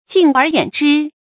注音：ㄐㄧㄥˋ ㄦˊ ㄧㄨㄢˇ ㄓㄧ
敬而遠之的讀法